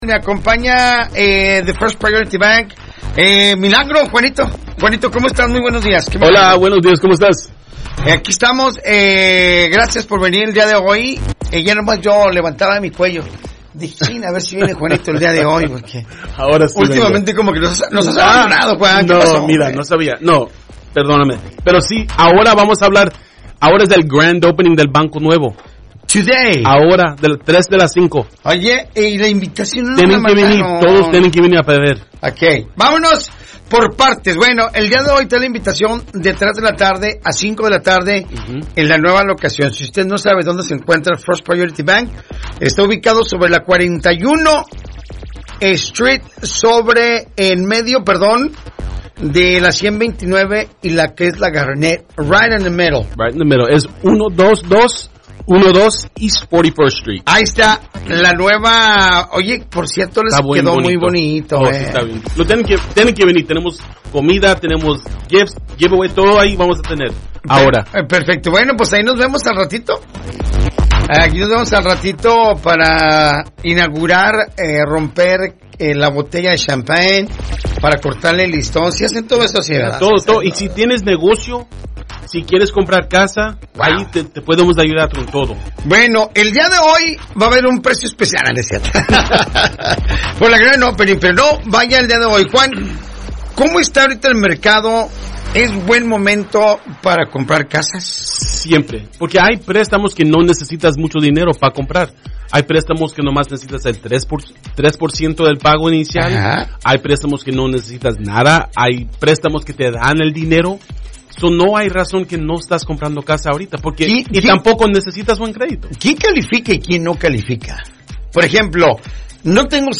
Entrevista-FirstPryorityBank-30Septiembre25.mp3